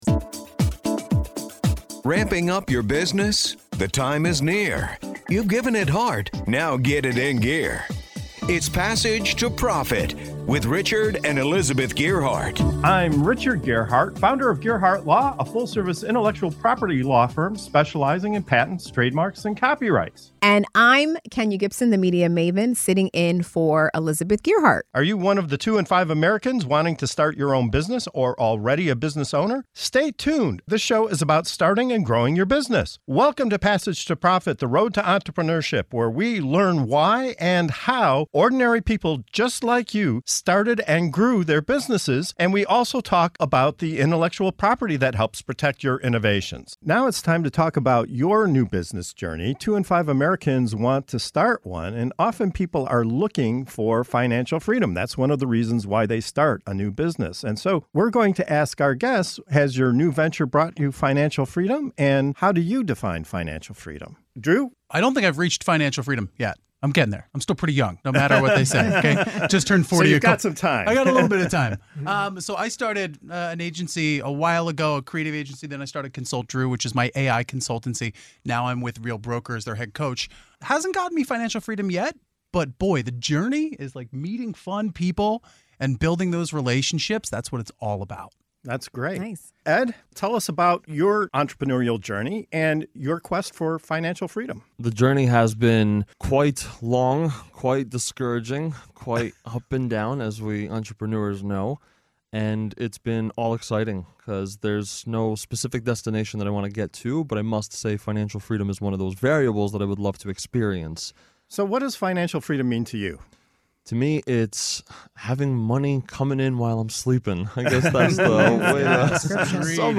In this segment of Your New Business Journey on Passage to Profit Show, we dive into the diverse journeys of three entrepreneurs in their quest for financial freedom. From starting creative agencies and AI consultancies to launching international real estate teams and nonprofit ventures, our guests share their personal definitions of financial freedom, the challenges they've faced, and the unique ways they're working towards that elusive goal.